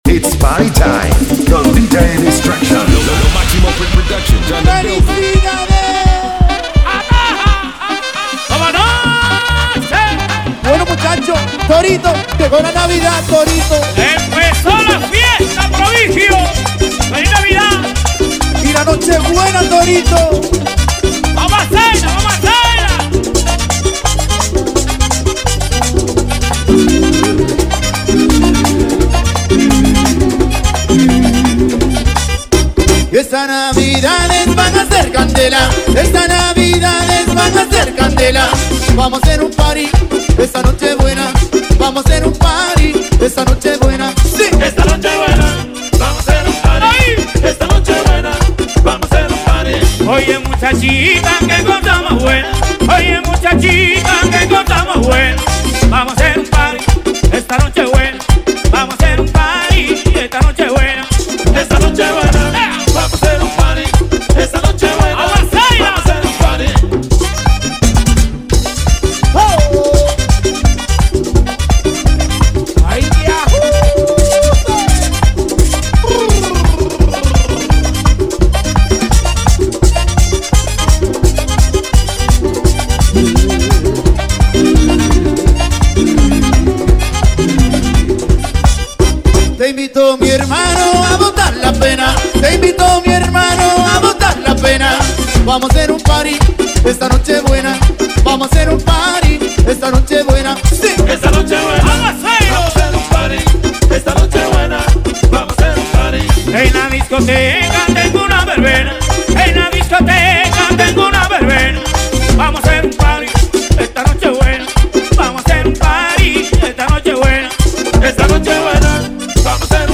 smooth bachata vibes